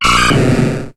Cri de Kaiminus dans Pokémon HOME.